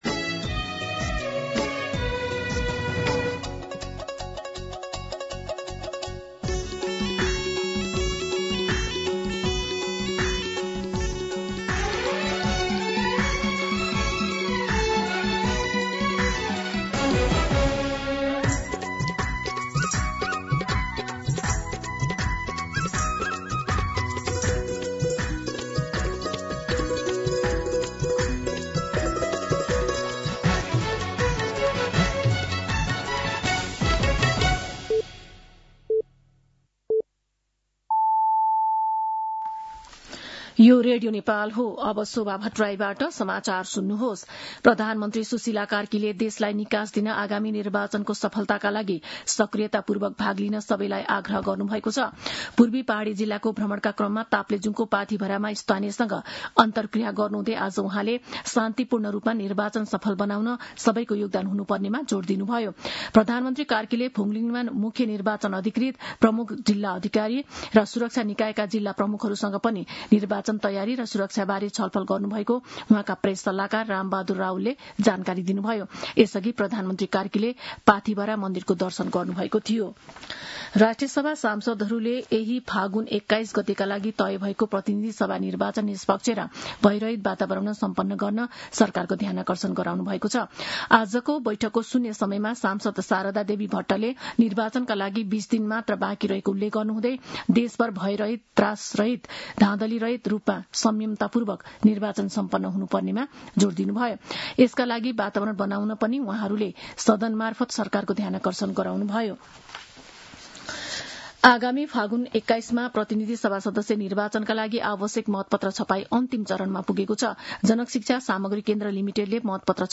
दिउँसो १ बजेको नेपाली समाचार : १ फागुन , २०८२